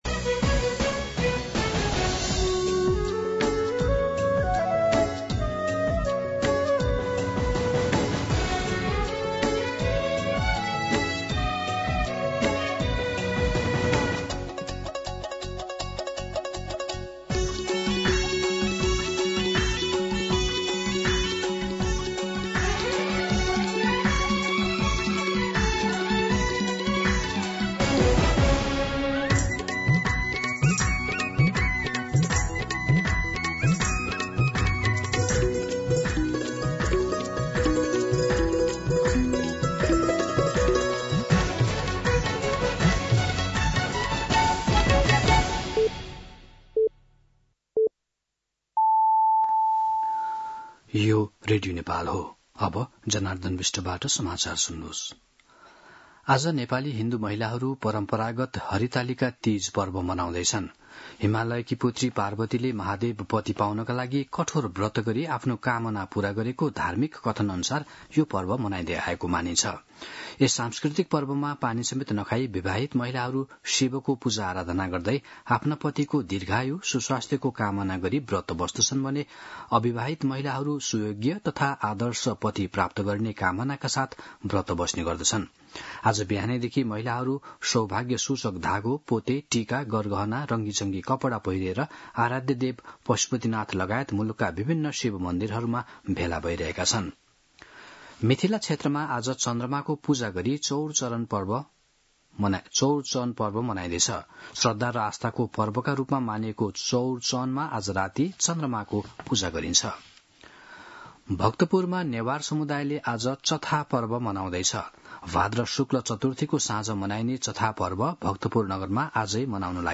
दिउँसो १ बजेको नेपाली समाचार : १० भदौ , २०८२
1pm-News-05-10.mp3